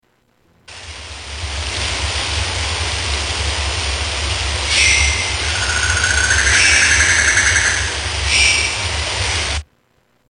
Rainforest Ambience 6
Category: Animals/Nature   Right: Personal